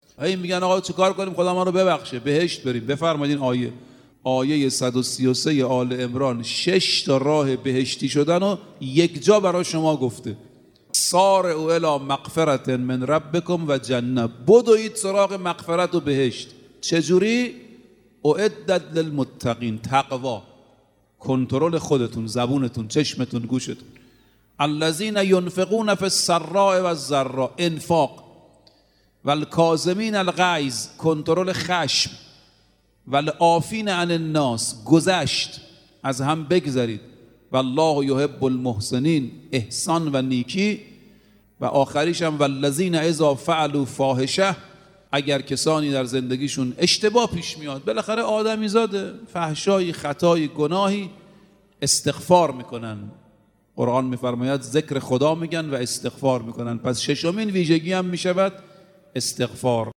در یکی از سخنرانی های خود